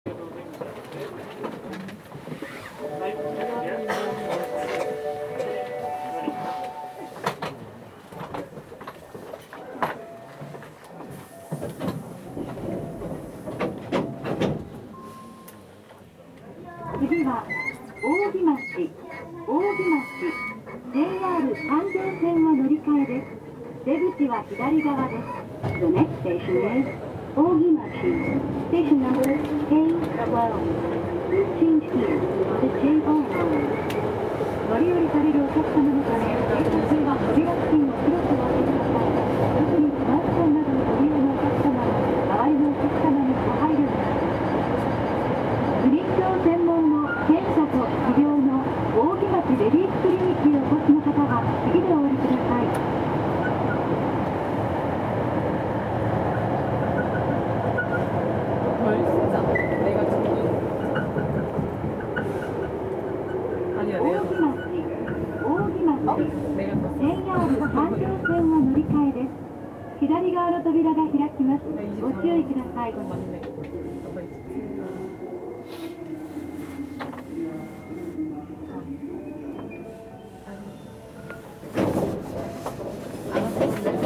走行機器は定格130kWのTDK831-A形を採用しており、抵抗制御でこれを制御します。
走行音
録音区間：南森町～扇町(お持ち帰り)